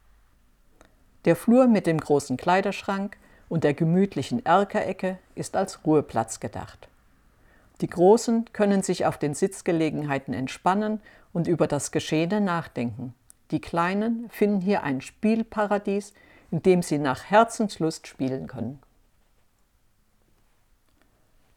Erleben Sie einen interessanten Rundgang durch unser Heimatmuseum "Altes Rathaus" in Loßburg und lassen Sie sich mit unserem Audioguide durch Raum und Zeit begleiten.